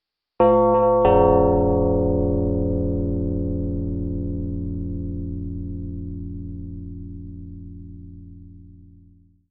large_doorbell
bell big ding dong door doorbell large ring sound effect free sound royalty free Sound Effects